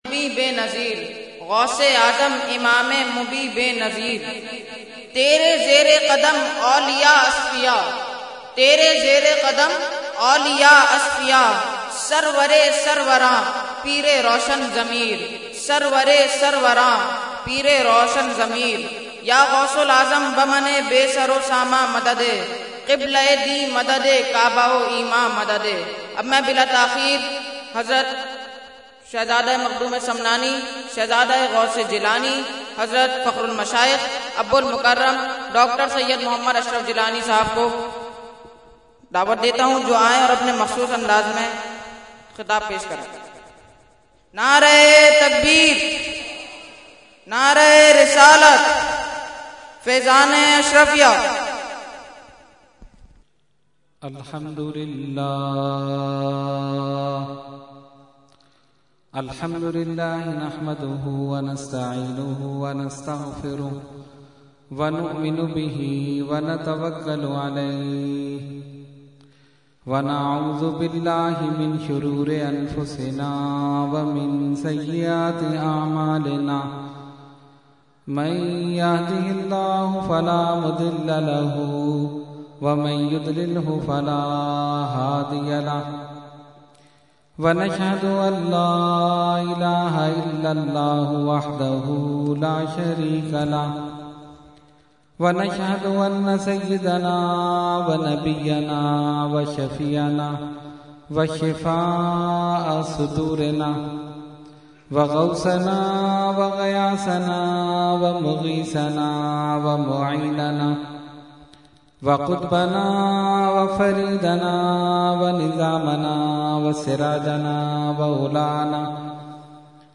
Category : Speech | Language : UrduEvent : Mehfil 11veen Nazimabad 23 March 2012